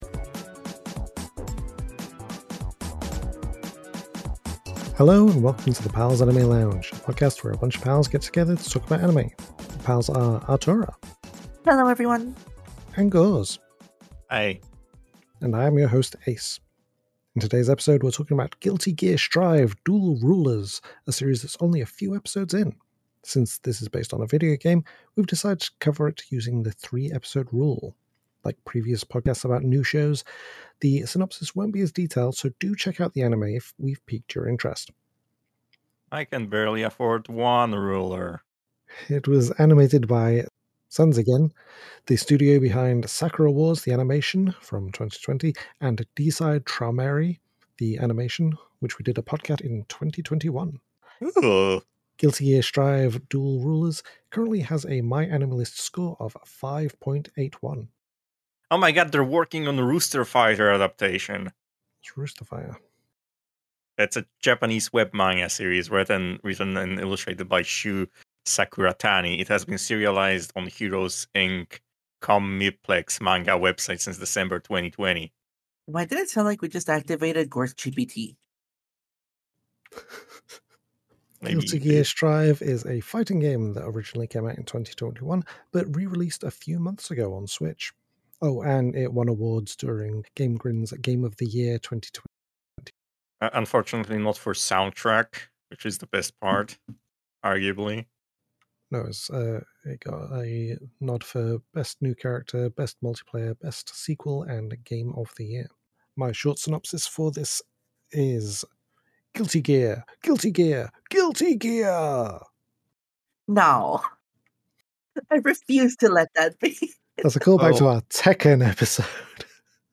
Welcome to the Pals Anime Lounge, a podcast where a bunch of pals get together to talk about anime!